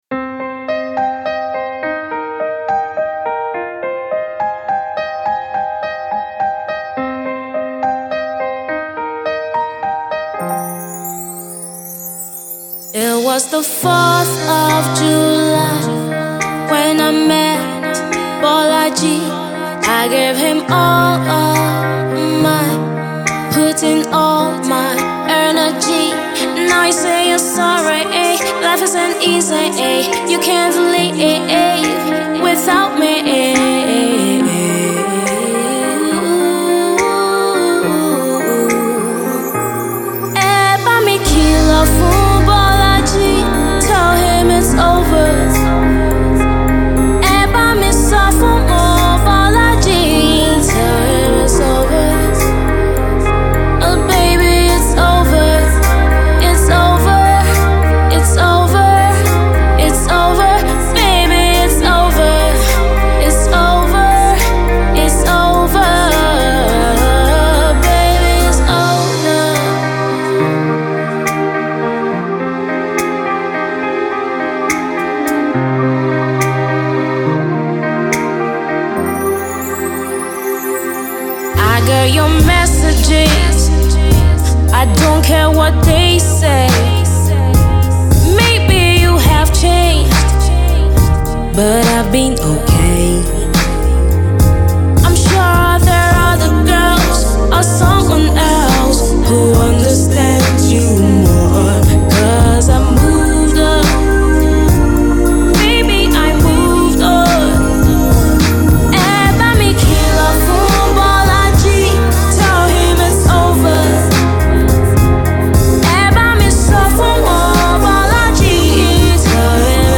My style of music is Afro soul, blues, jazz, folk and swing.